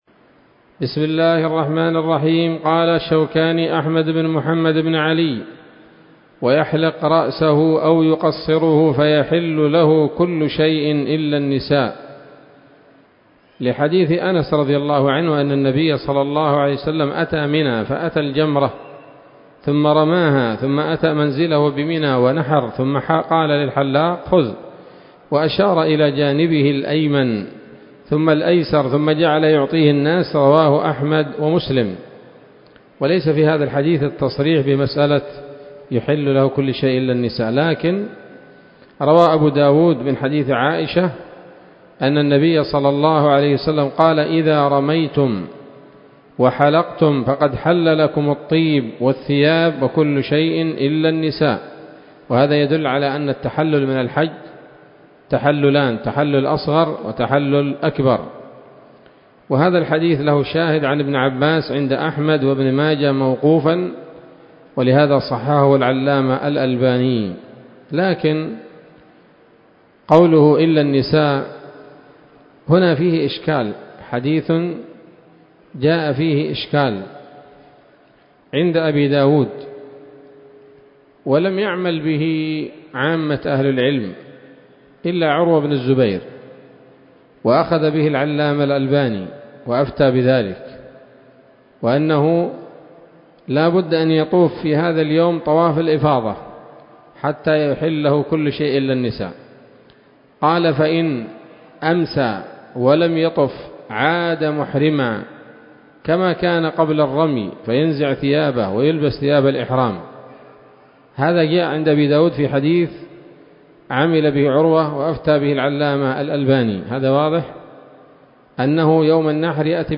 الدرس الثاني والعشرون من كتاب الحج من السموط الذهبية الحاوية للدرر البهية